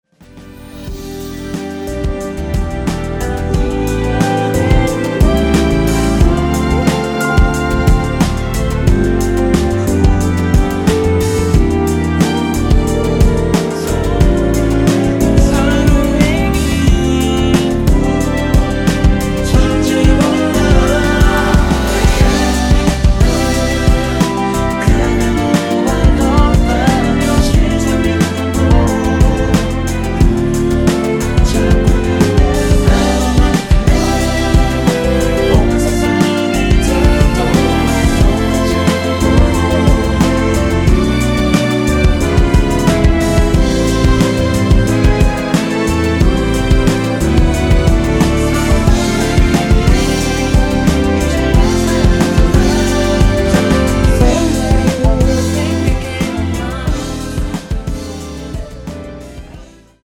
원키에서(-3)내린 멜로디와 코러스 포함된 MR입니다.(미리듣기 확인)
앞부분30초, 뒷부분30초씩 편집해서 올려 드리고 있습니다.
중간에 음이 끈어지고 다시 나오는 이유는